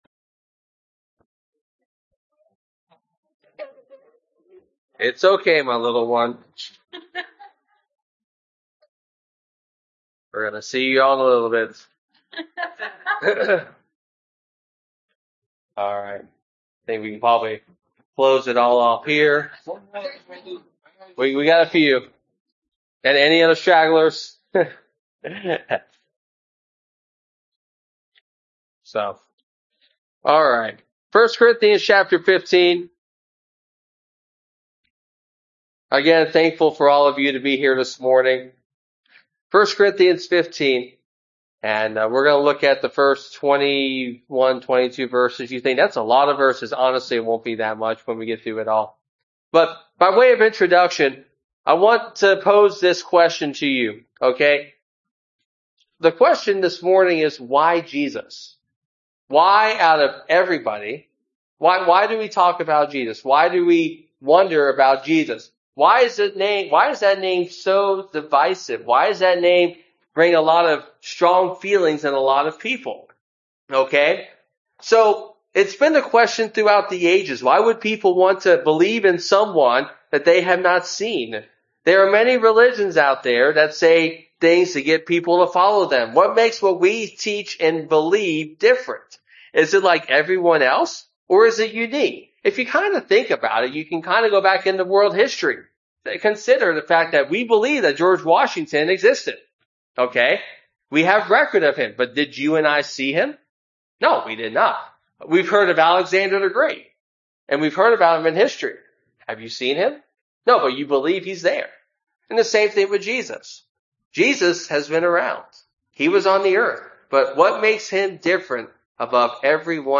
Exposition of Corinthians Passage: 1 Corinthians 15:1-22 Service Type: Holiday Preaching (voice only) Download Files Bulletin « Why is Jesus the Savior That Saves Us?
sermon-April-5-2026.mp3